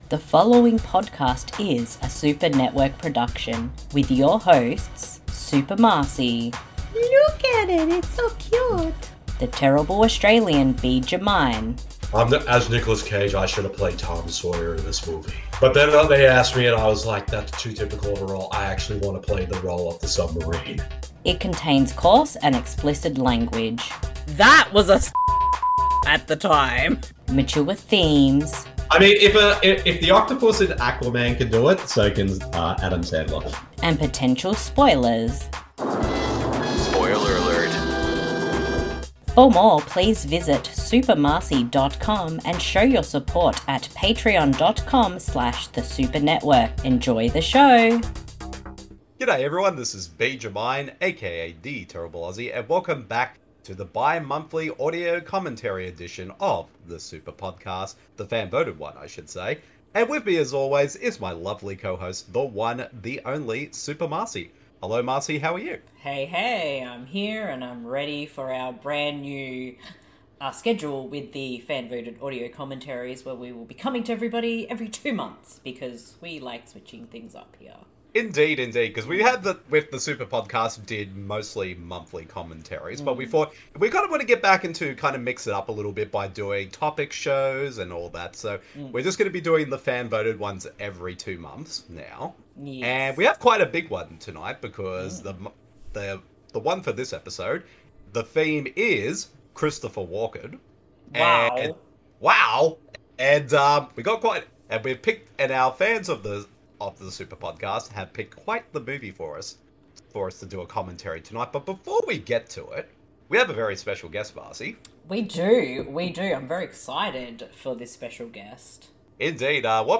Audio Commentary/Watch A Long podcast episode
Brace yourselves: so many Christopher Walken impressions are coming